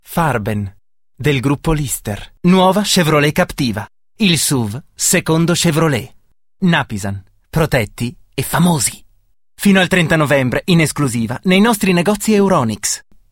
Sprecher Italienischer Muttersprache in der Schweiz.
Sprechprobe: Werbung (Muttersprache):